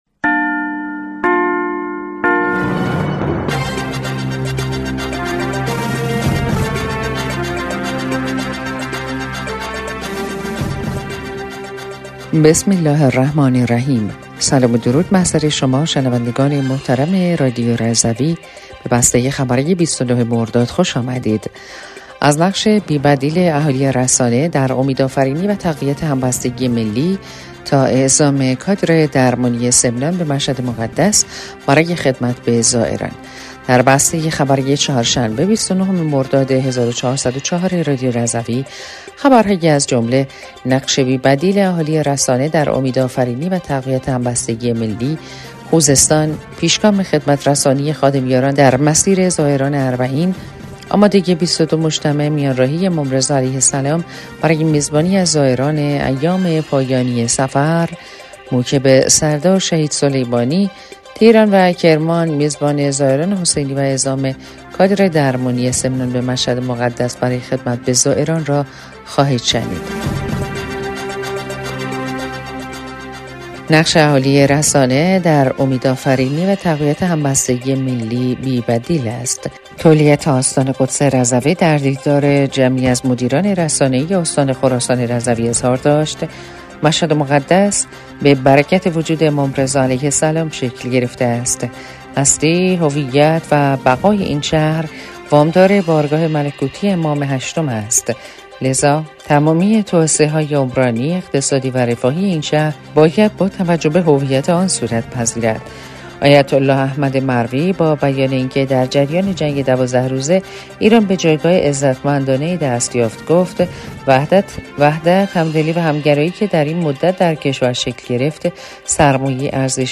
بسته خبری ۲۹ مرداد ۱۴۰۴ رادیو رضوی/